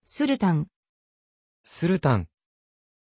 Aussprache von Sultan
pronunciation-sultan-ja.mp3